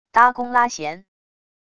搭弓拉弦wav音频